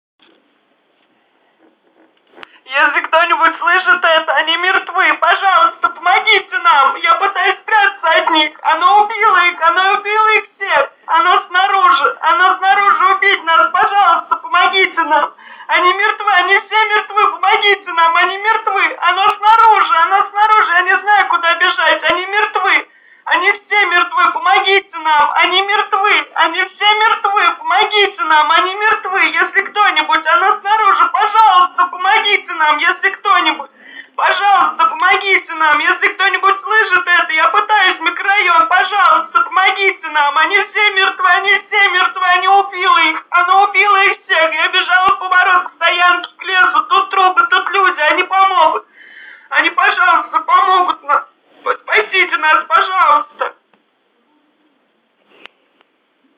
Звуки крика людей
Крик отчаянной помощи